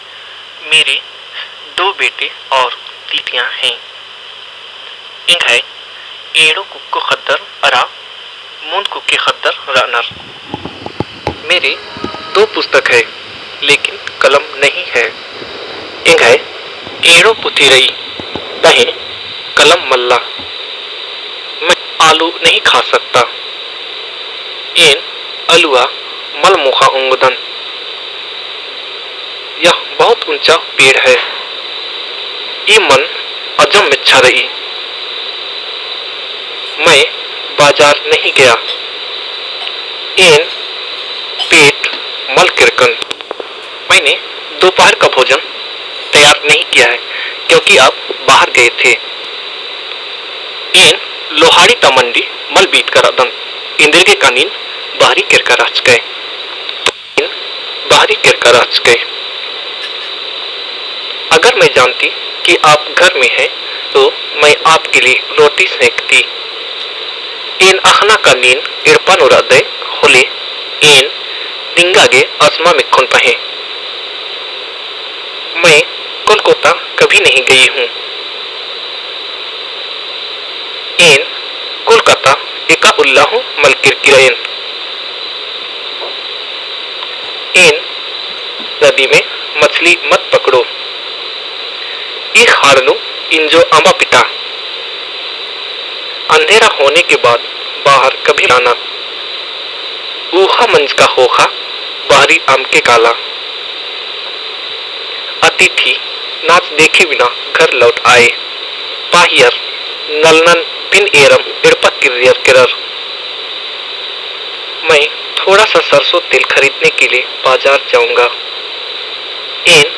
a WAV file converted from tape recording